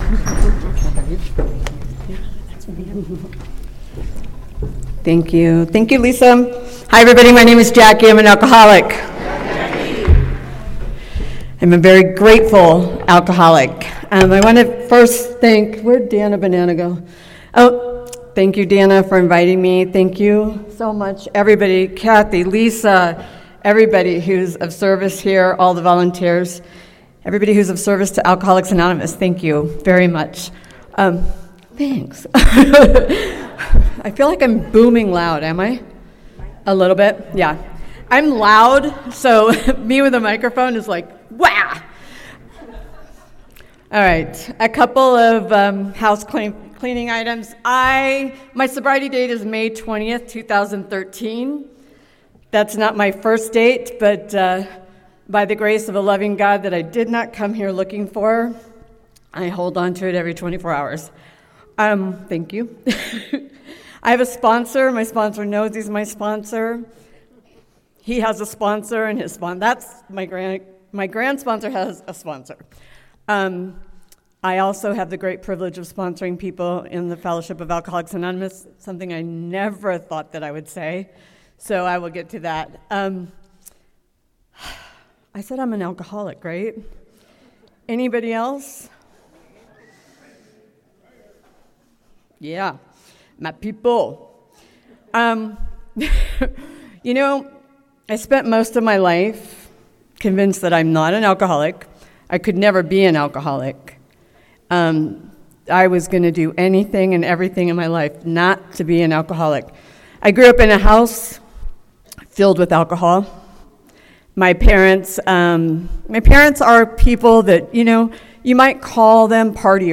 33rd Indian Wells Valley AA Roundup with Al-Anon and NA